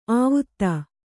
♪ āvutta